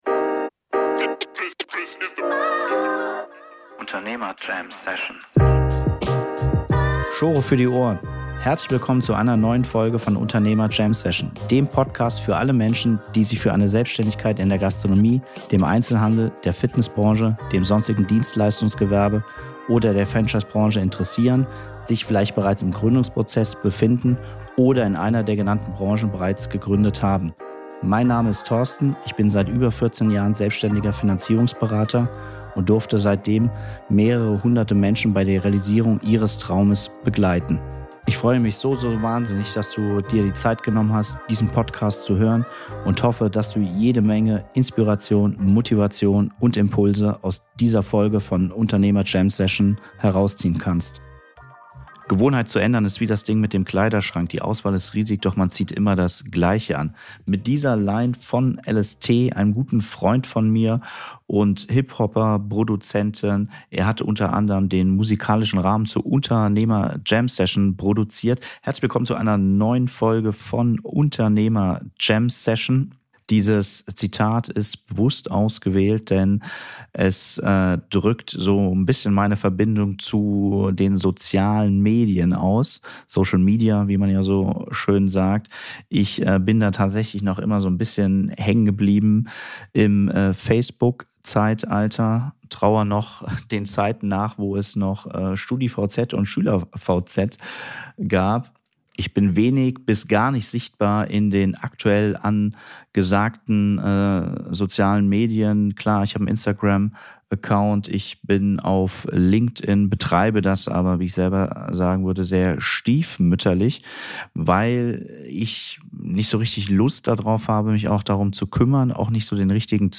Aus der Hilflosigkeit in die Sichtbarkeit mit Social Media. Ein Gespräch mit Videograf